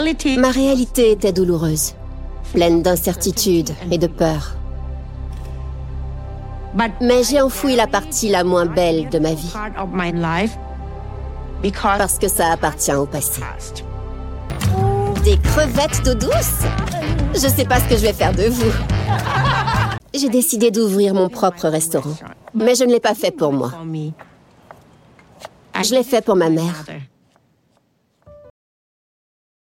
Documentaire / Voice-over